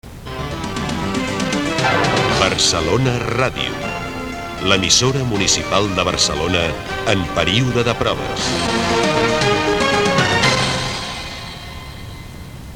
Identificació de l'emissora en període de proves